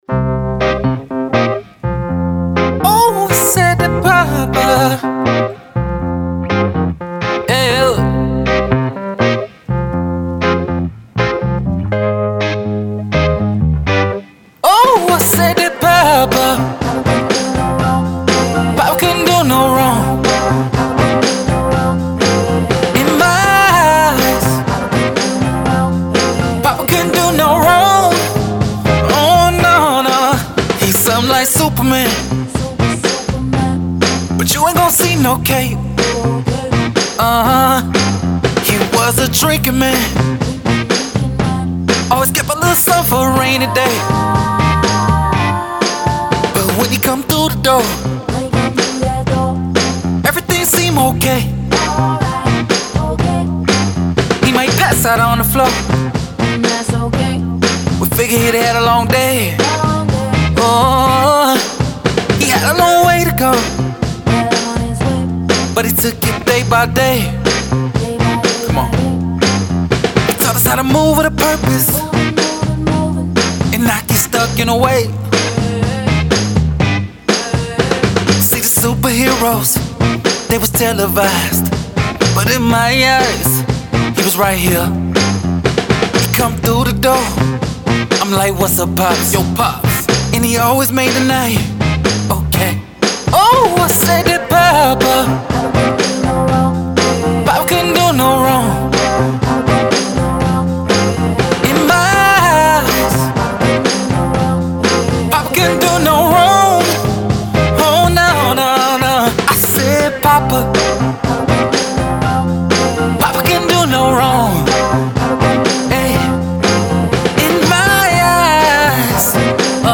Soul, 60s
C Minor